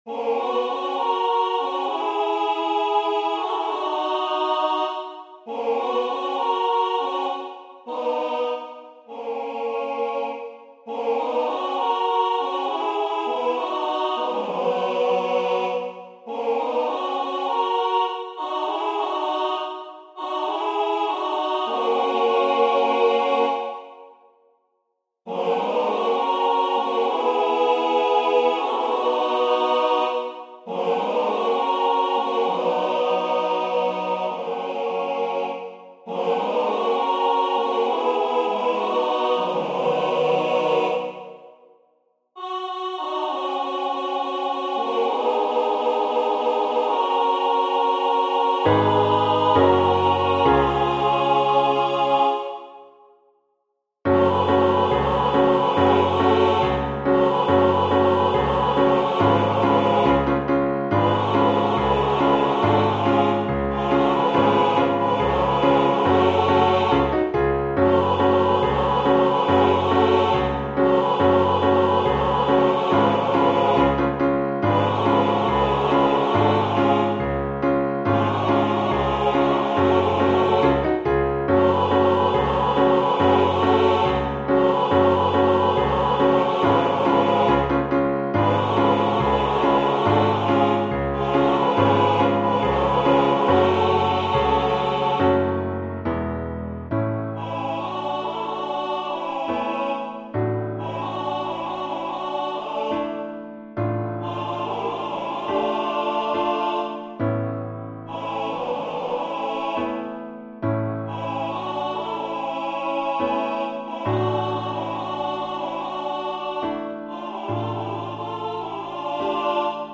CHÓR